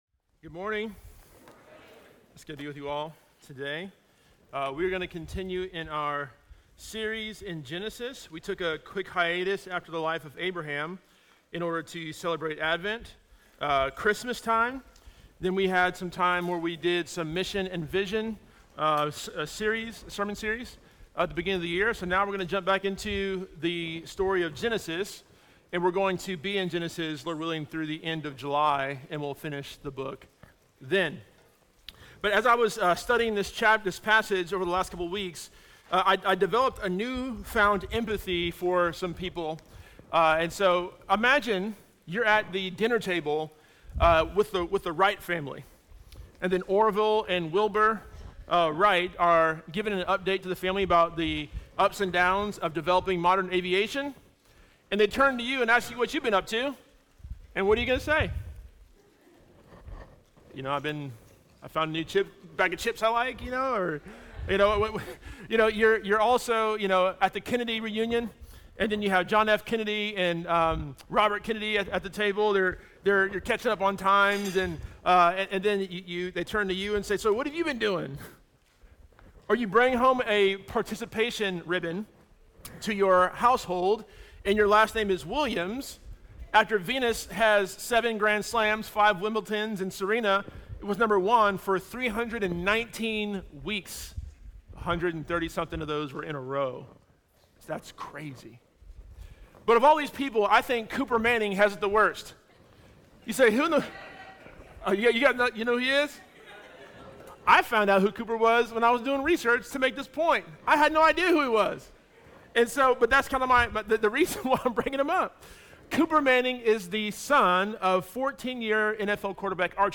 Sermons from Imago Dei Church in Raleigh North Carolina